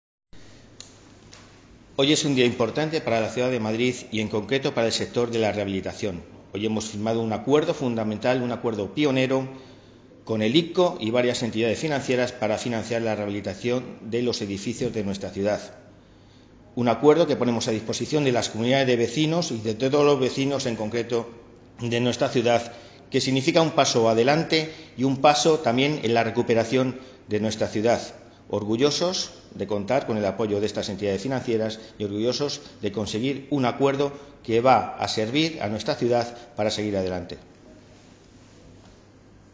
Nueva ventana:El delegado del Área Delegada de Vivienda, Álvaro González, muestra su satisfacción por el acuerdo firmado hoy
Declaraciones del delegado de Vivienda.mp3